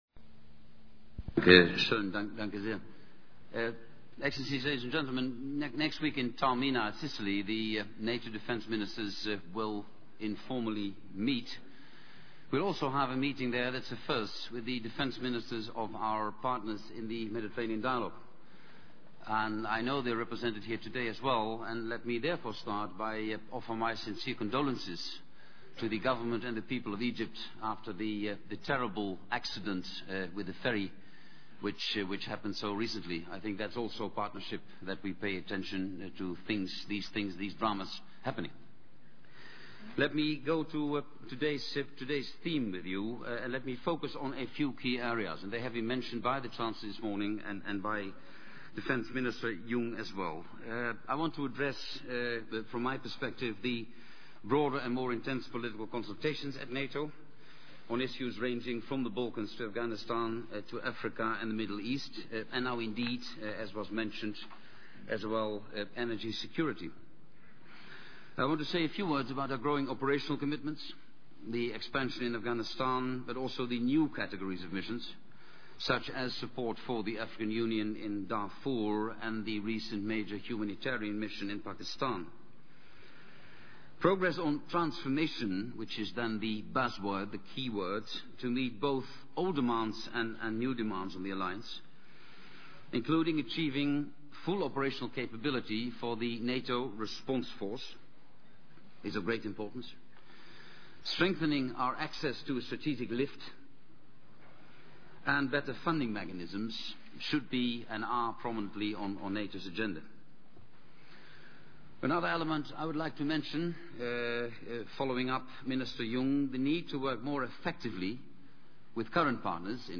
Speech by NATO Secretary General, Jaap de Hoop Scheffer, at the 42nd Munich Conference on Security Policy